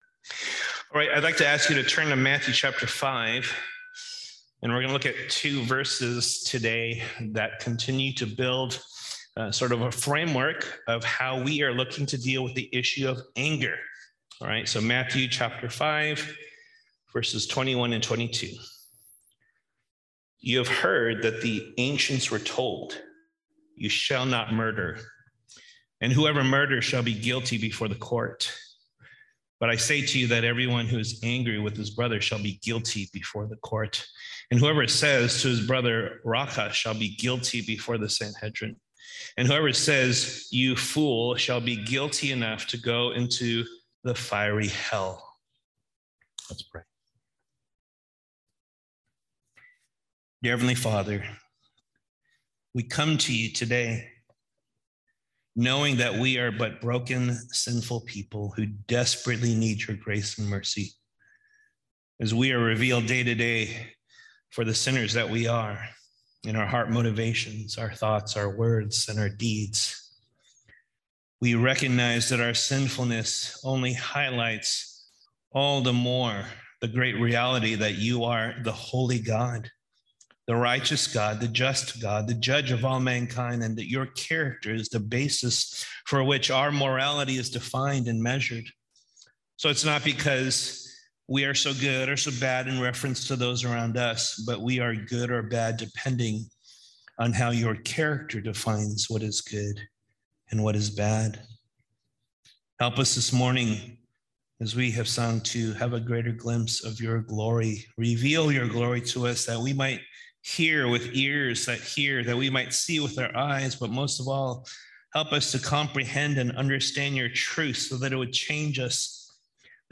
August 14, 2022 (Sunday Service)